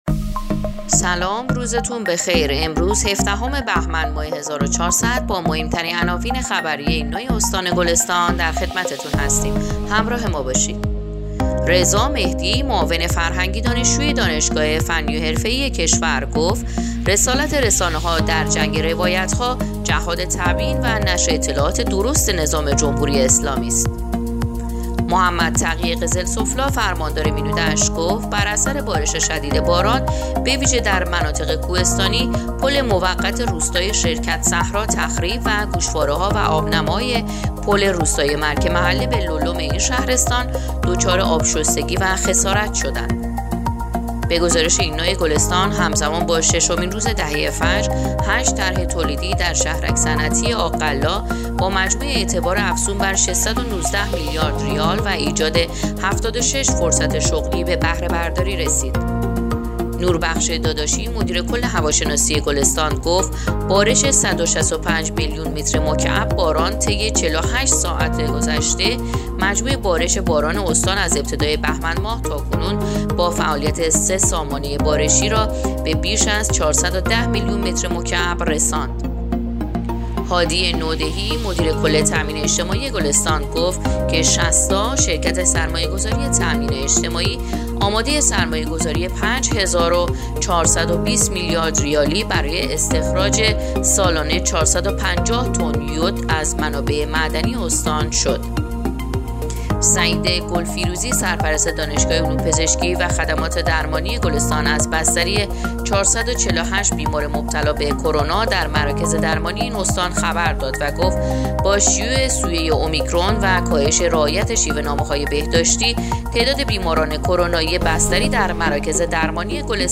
پادکست/ اخبار شبانگاهی هفدهم بهمن ماه ایرنا گلستان